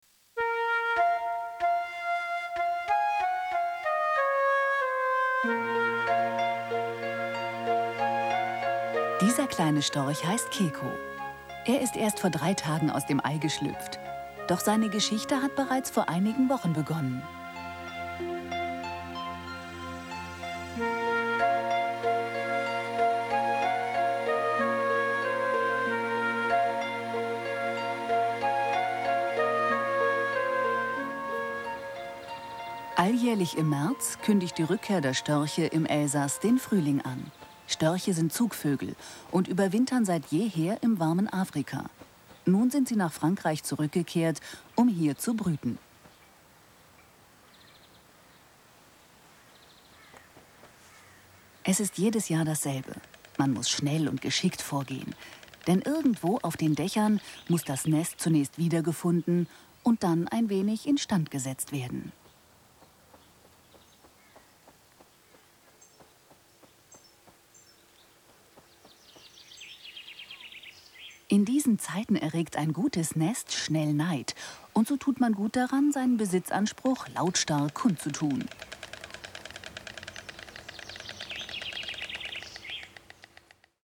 Flexible Stimme, breites Einsatzgebiet und große Spielfreude. mittlere Stimmlage facettenreich + flexibel Spaß + Spielfreude
Sprechprobe: Industrie (Muttersprache):